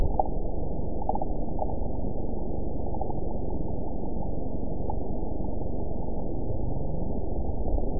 event 913936 date 04/24/22 time 01:36:09 GMT (3 years ago) score 9.11 location TSS-AB05 detected by nrw target species NRW annotations +NRW Spectrogram: Frequency (kHz) vs. Time (s) audio not available .wav